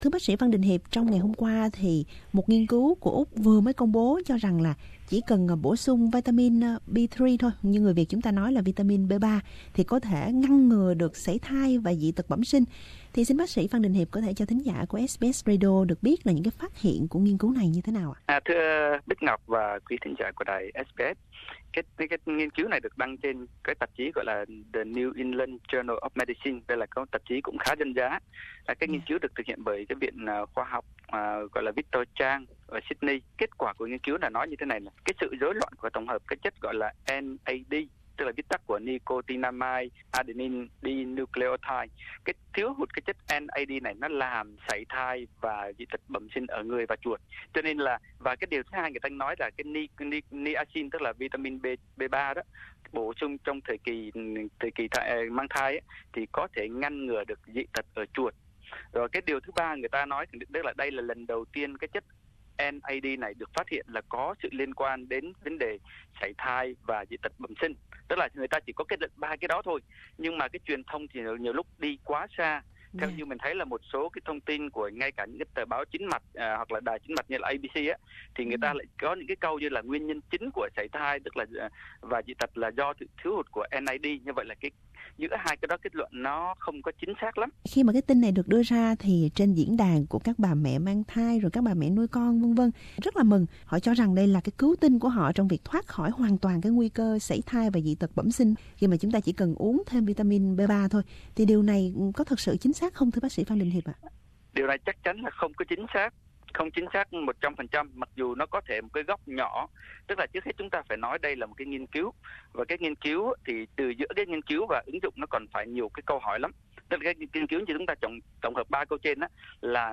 trả lời các câu hỏi của SBS Radio